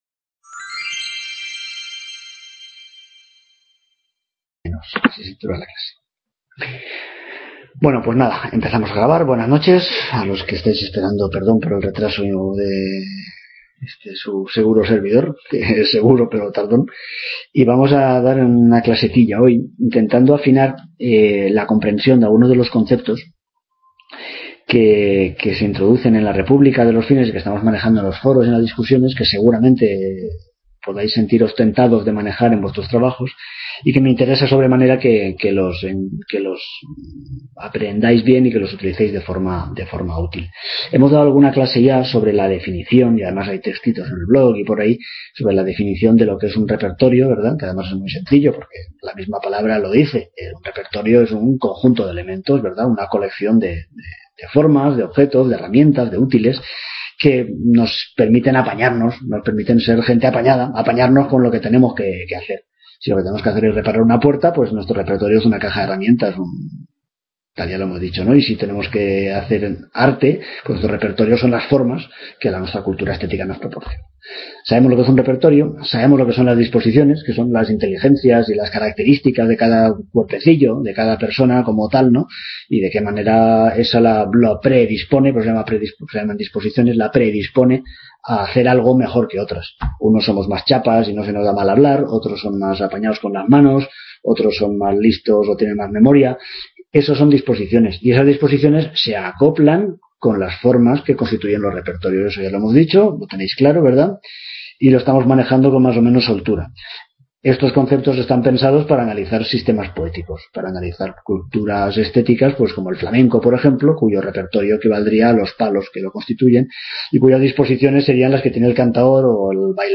Video Clase